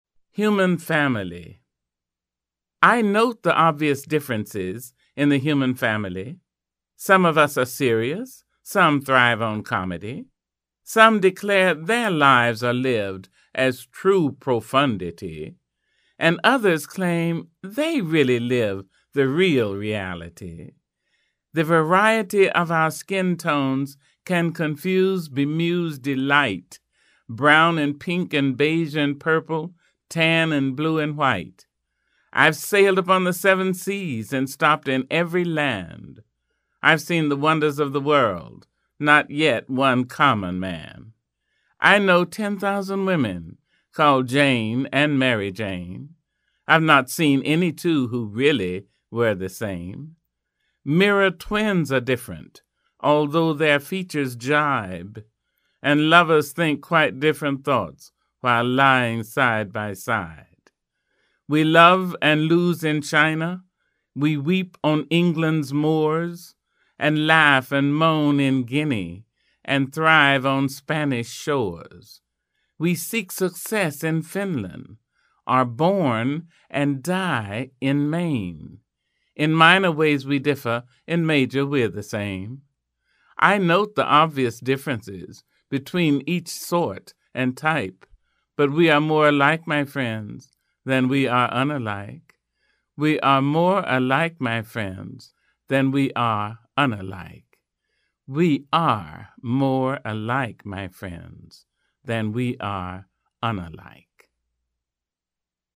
Maya Angelou (April 4, 1928–May 28, 2014) reading her poem "The Human Family."